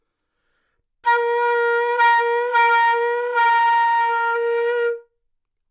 长笛单音（吹得不好） " 长笛 Asharp4 坏的丰富性
描述：在巴塞罗那Universitat Pompeu Fabra音乐技术集团的goodsounds.org项目的背景下录制。单音乐器声音的Goodsound数据集。 instrument :: flutenote :: Asharpoctave :: 4midi note :: 58microphone :: neumann U87tuning reference :: 442goodsoundsid :: 3173 故意扮演一个富裕的例子
标签： 好声音 单注 多样本 Asharp4 纽曼和U87 长笛
声道立体声